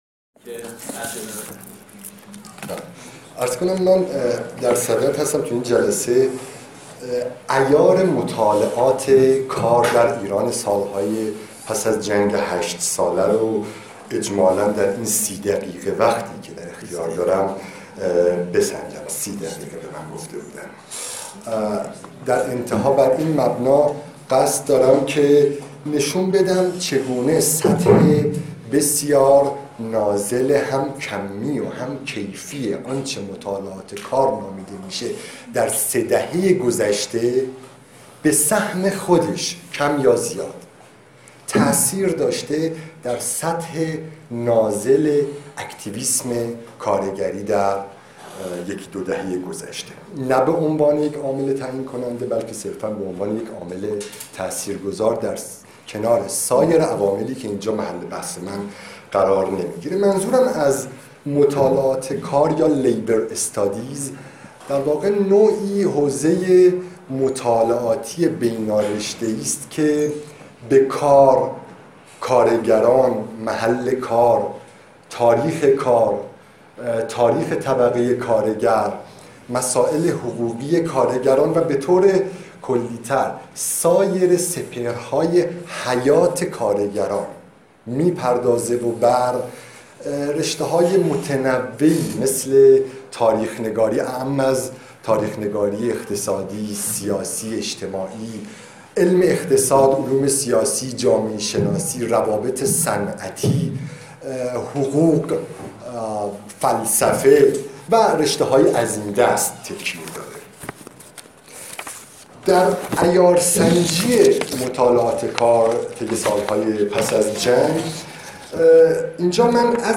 دو سخنرانی و پرسش و پاسخ در دانشگاه امام صادق